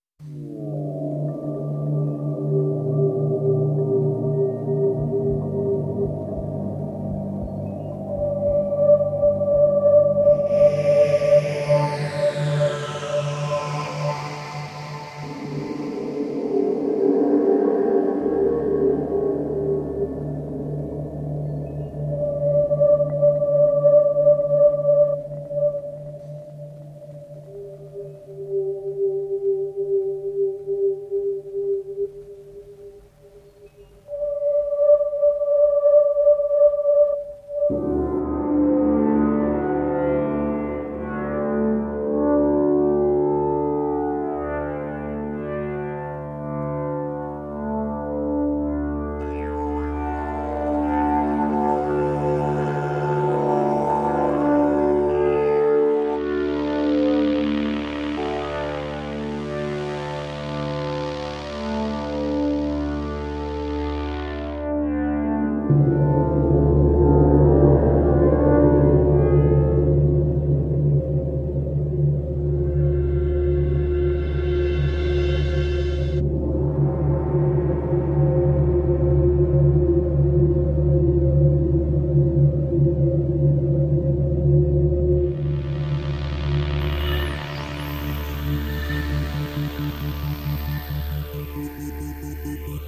Звуки мистики
Звучание волшебного окружения